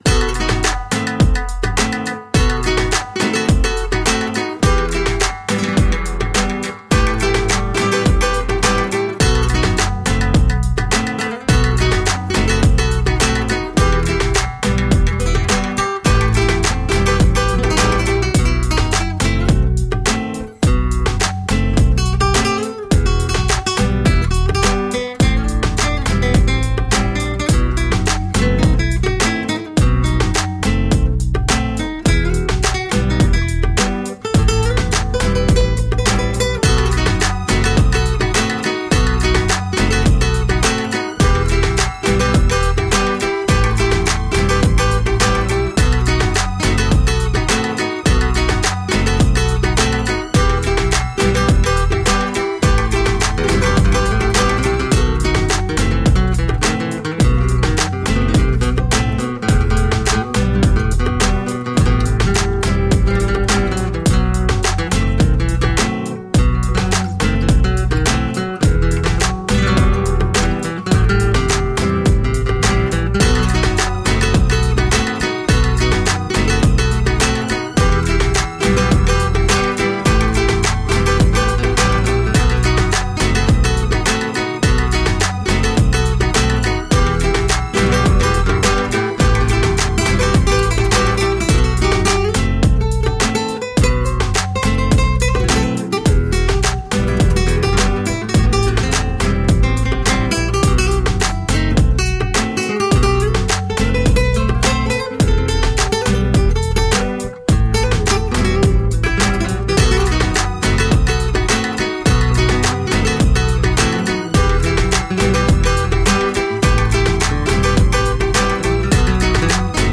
Urban flamenco.
Tagged as: World, Latin, World Influenced